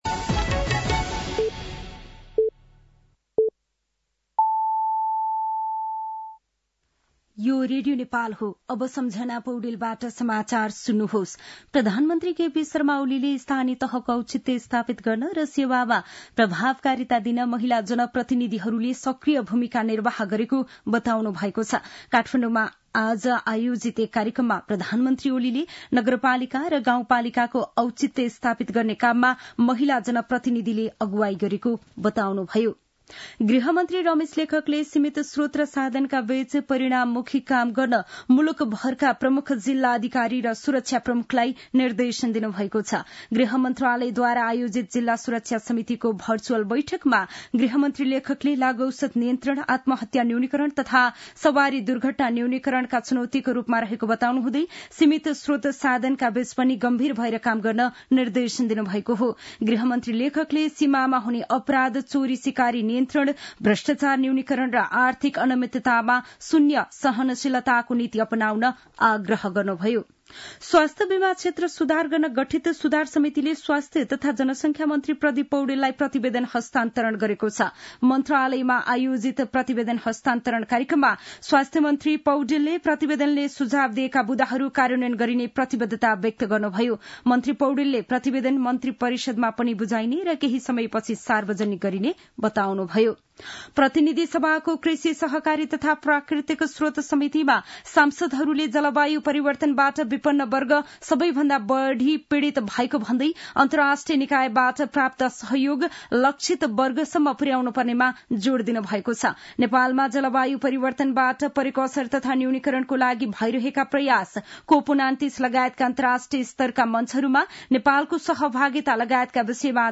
An online outlet of Nepal's national radio broadcaster
साँझ ५ बजेको नेपाली समाचार : २० पुष , २०८१
5-pm-nepali-news-9-19.mp3